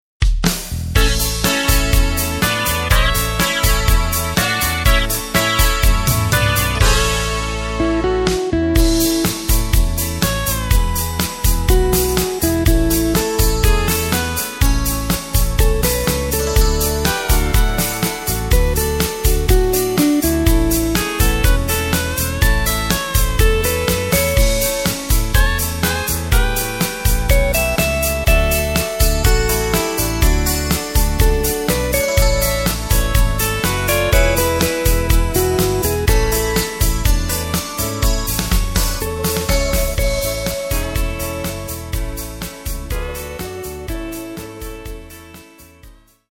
Takt:          4/4
Tempo:         123.00
Tonart:            A
Country-Beat aus dem Jahr 2010!